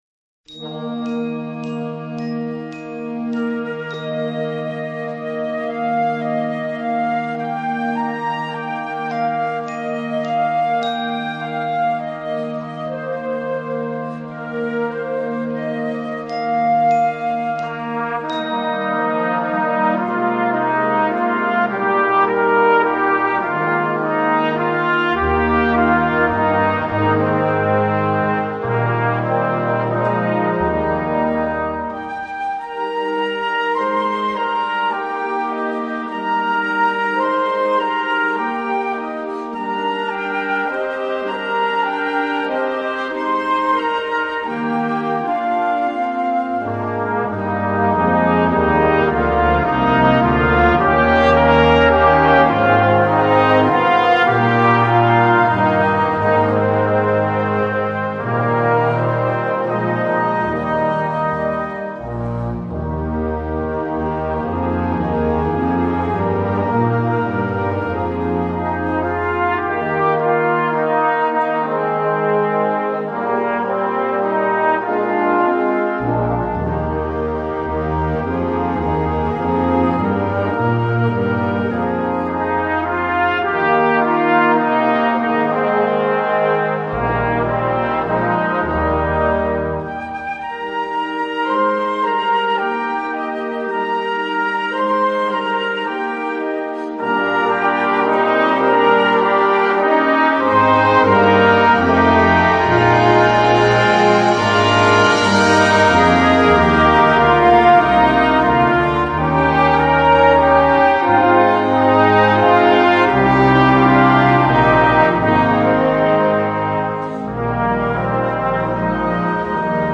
Scottish Folk Song
Besetzung: Blasorchester
tender and lyrical folk song